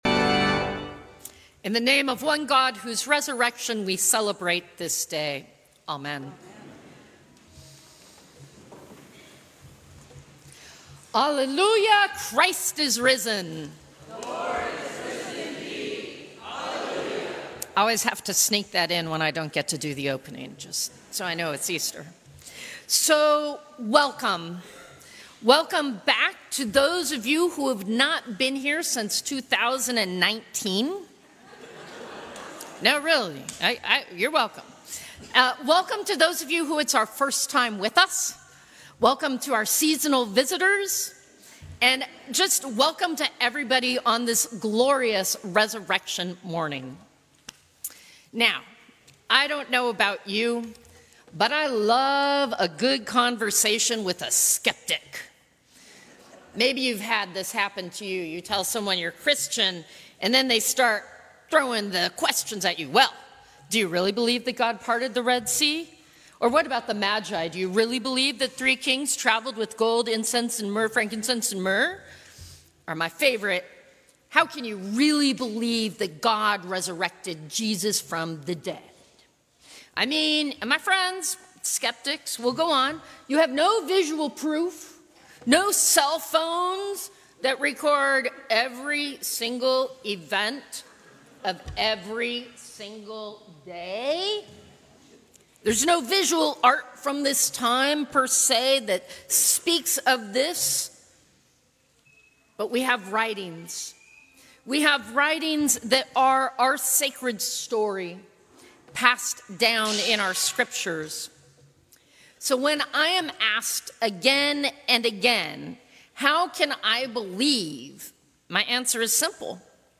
Sermons from St. Cross Episcopal Church Easter Day Jan 08 2024 | 00:13:17 1x 00:00 / 00:13:17 Subscribe Share Apple Podcasts Spotify Overcast RSS Feed Share Link Embed